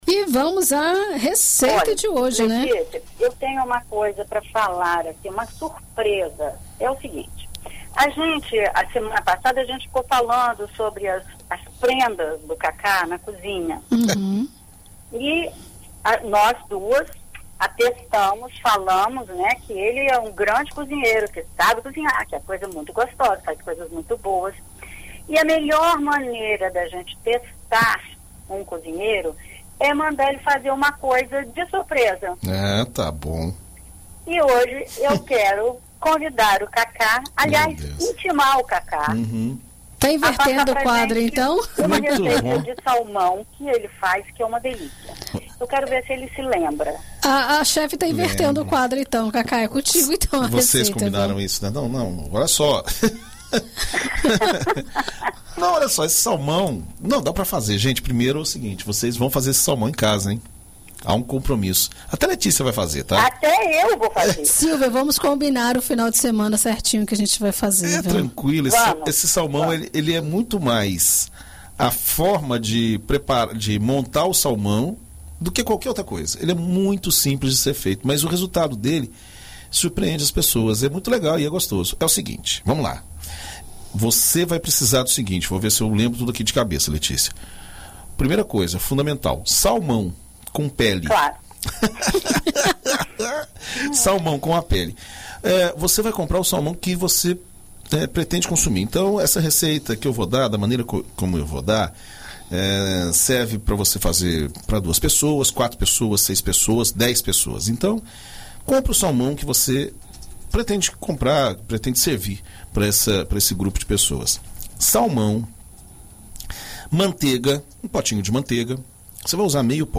Na coluna Conversa de Cozinha desta sexta-feira (05), na BandNews FM Espírito Santo